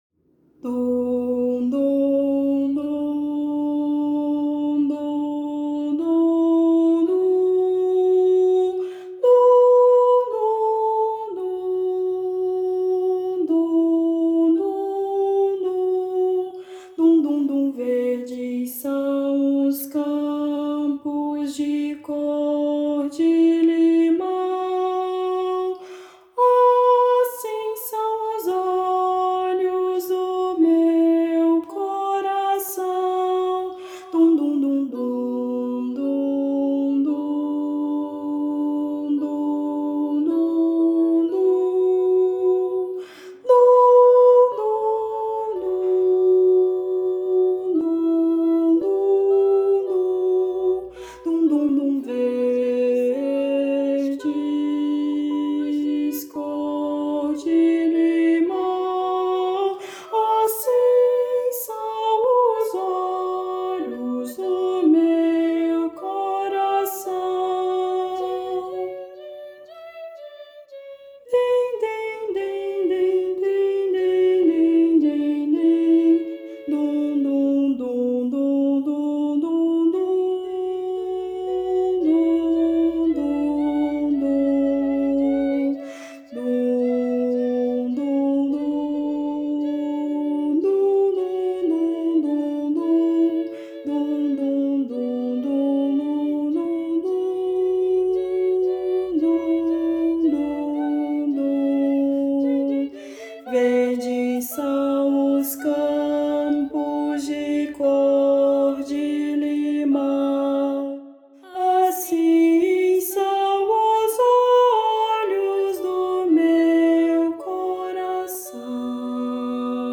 para coro infantil a três vozes
Voz Guia 3